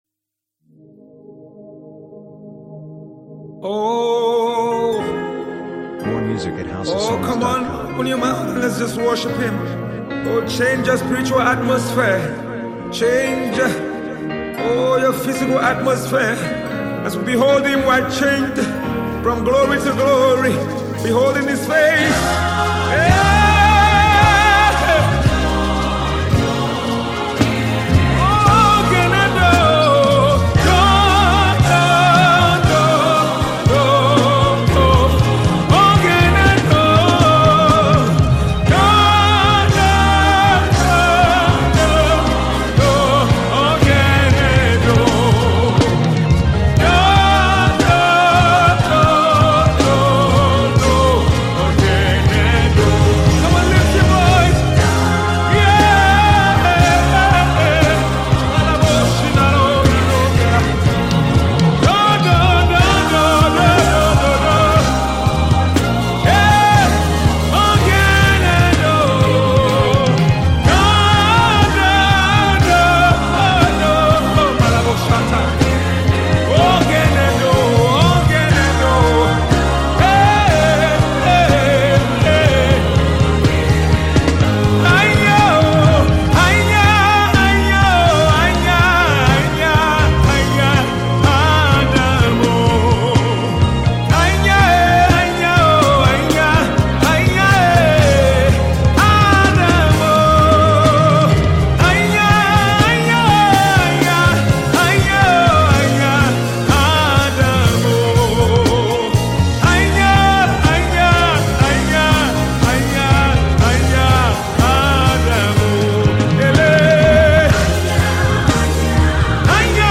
Tiv songs
encouraging, uplifts the spirit and soul